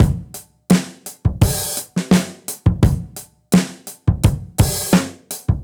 Index of /musicradar/dusty-funk-samples/Beats/85bpm
DF_BeatC_85-04.wav